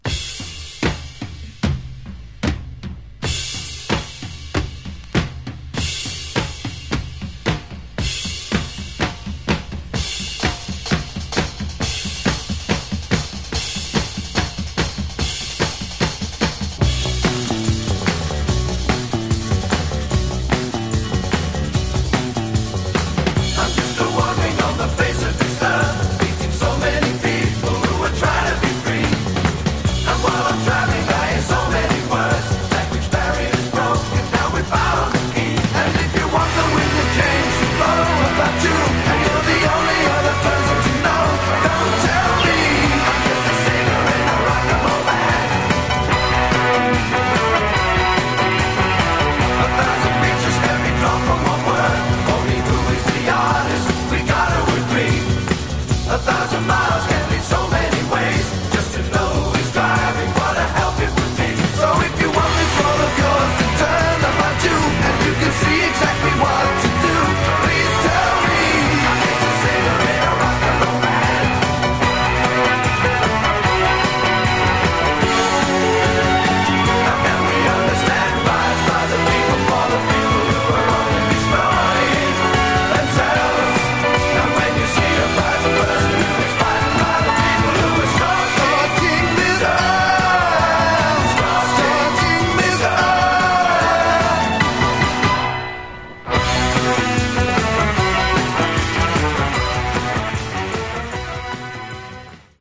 Genre: Progressive Rock